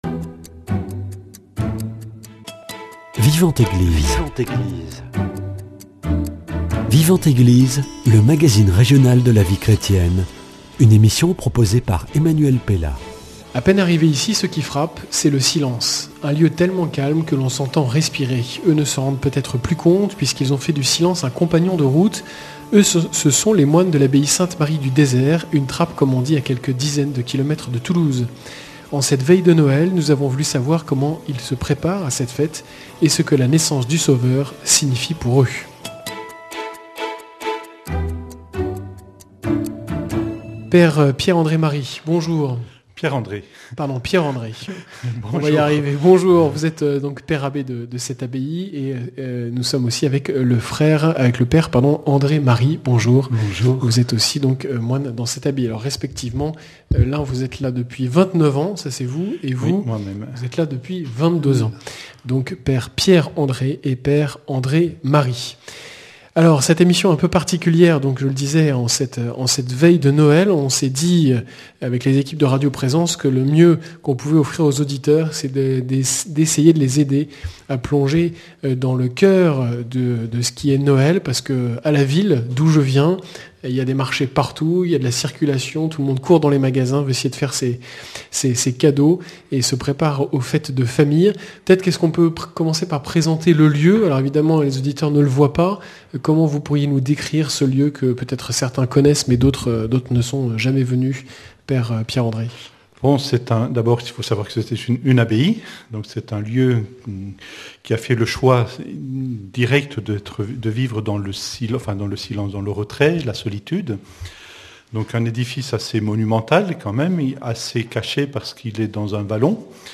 A peine arrivé ici, ce qui frappe c’est le calme. Un lieu tellement calme que l’on s’entend respirer.
Une « trappe » comme on dit, à quelques dizaine de kilomètres de Toulouse.